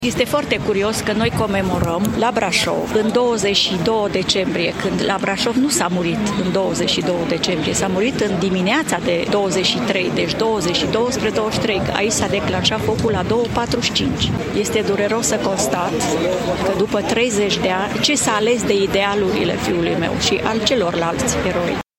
Aproximativ 200 de oameni au participat la momentul solemn care a avut loc la Cimitirul Eroilor din centrul Brașovului.